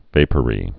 (vāpə-rē)